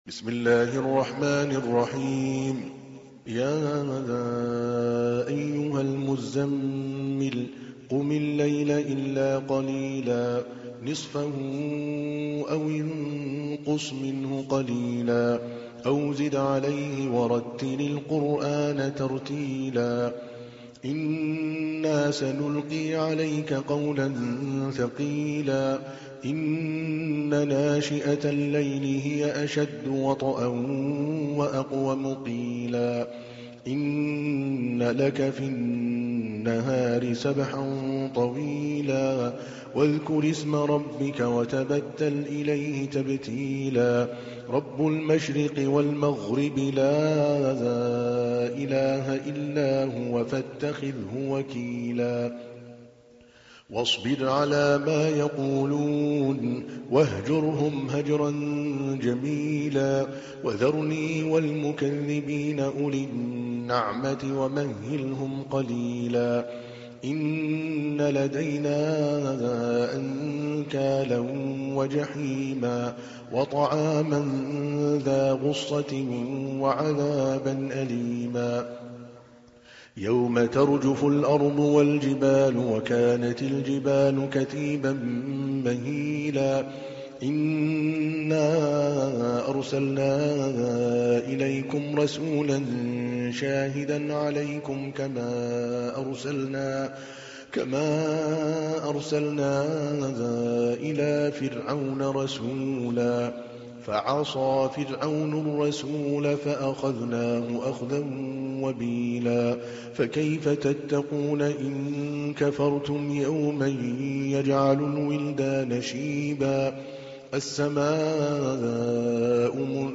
تحميل : 73. سورة المزمل / القارئ عادل الكلباني / القرآن الكريم / موقع يا حسين